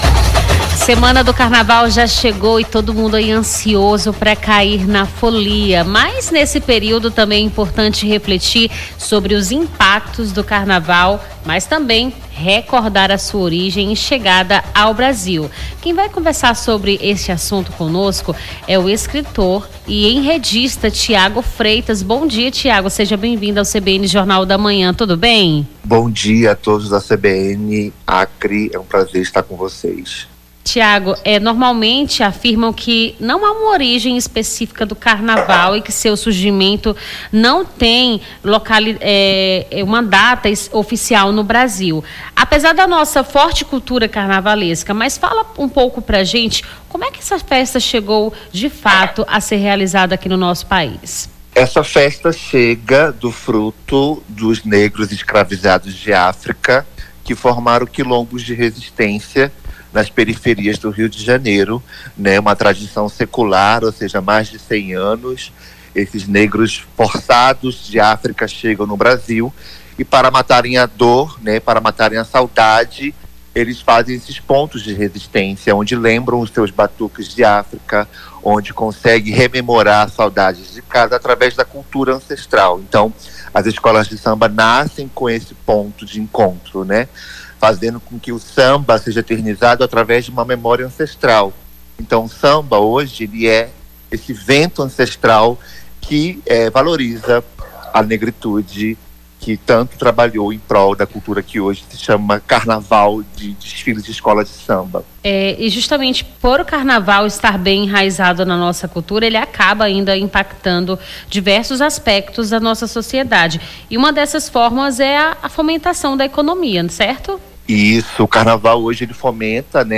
Nome do Artista - CENSURA - ENTREVISTA IMPACTO DO CARNAVAL (25-02-25).mp3